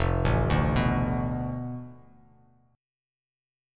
F6b5 Chord
Listen to F6b5 strummed